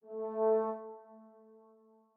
strings7_17.ogg